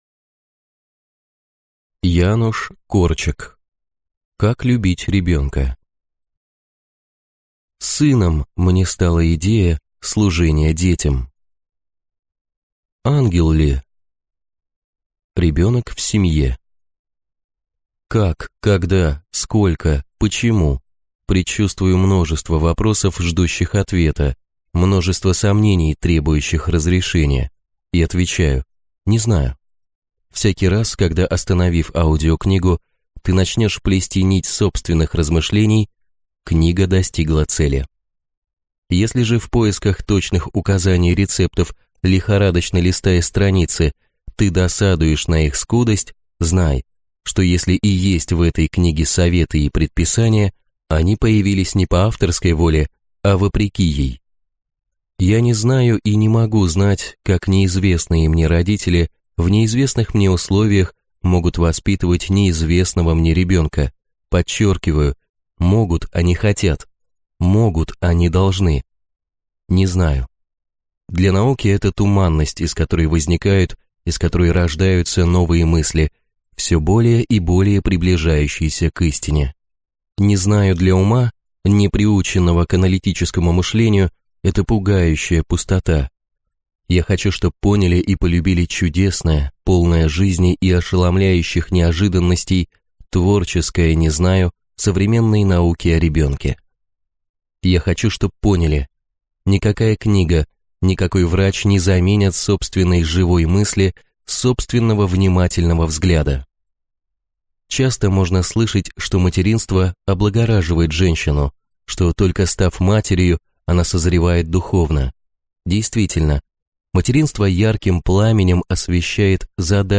Аудиокнига Как любить ребенка | Библиотека аудиокниг
Прослушать и бесплатно скачать фрагмент аудиокниги